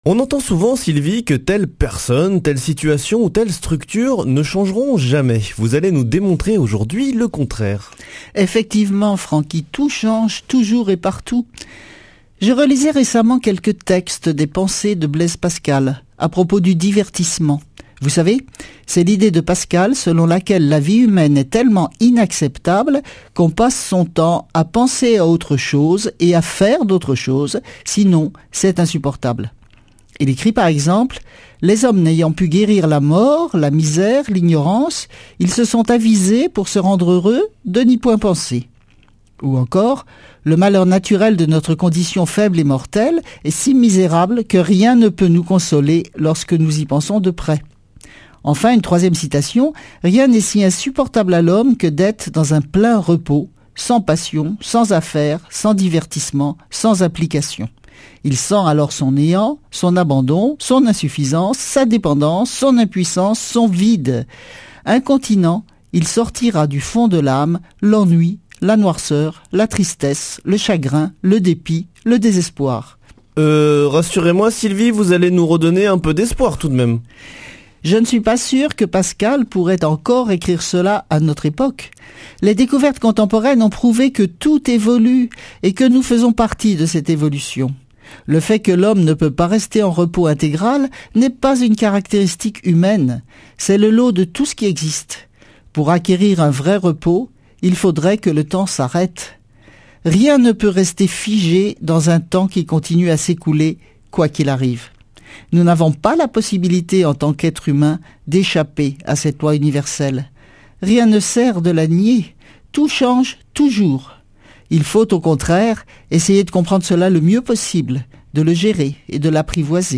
Speech
Une émission présentée par
Astrophysicienne